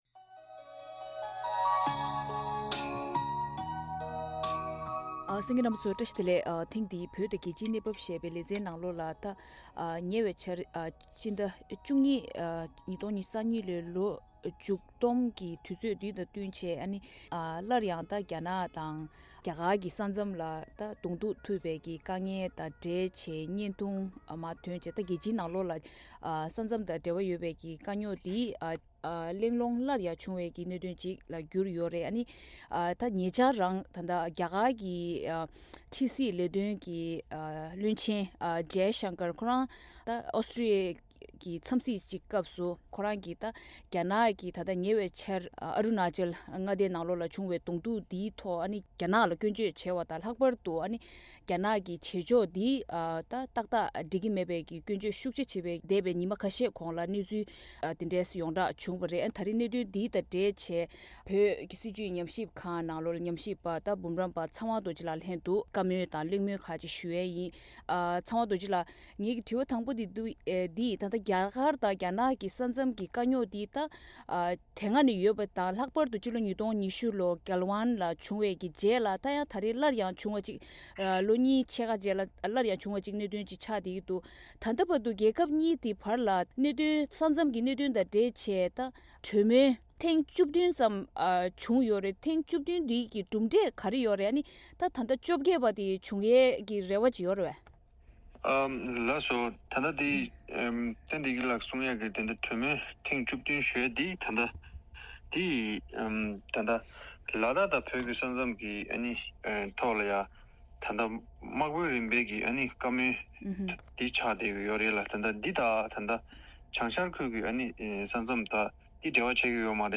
གླེང་མོལ་